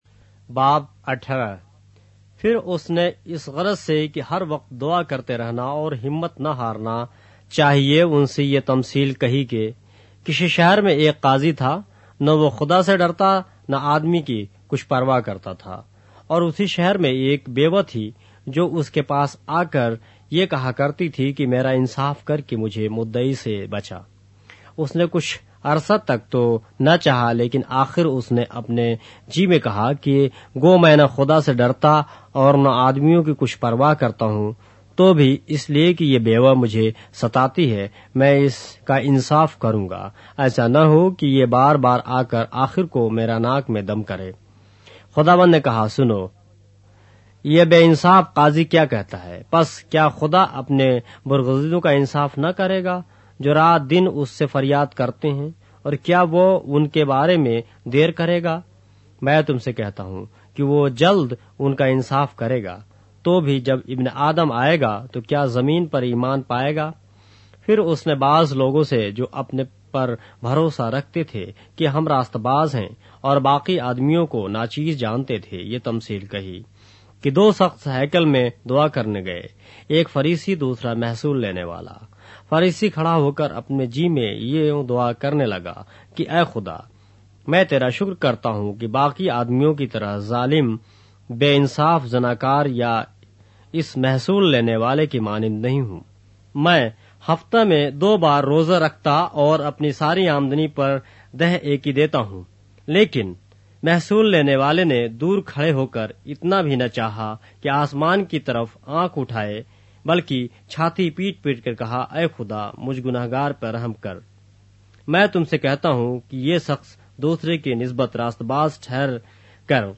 اردو بائبل کے باب - آڈیو روایت کے ساتھ - Luke, chapter 18 of the Holy Bible in Urdu